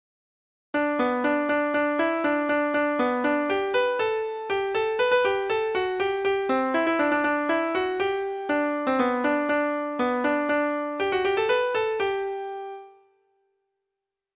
Treble Clef Instrument version
Folk Songs
Reels